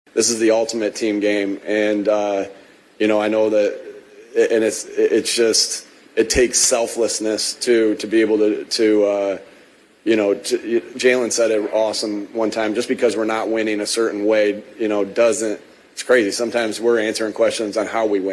Eagles coach Nick Sirianni, the former IUP wide receivers coach, says the win was a tribute to the sacrifices demanded of NFL champions.
nws0580-nick-sirianni-sacrifice.mp3